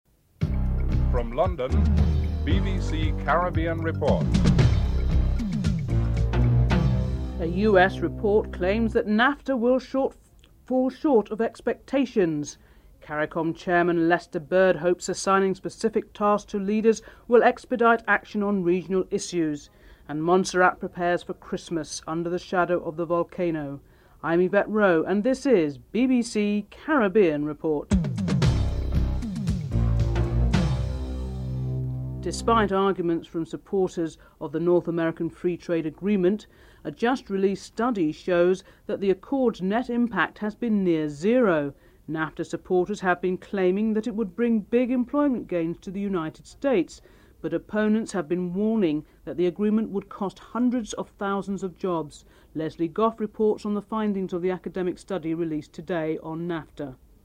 1. Headlines (00:00-00:33)
Montserratians are interviewed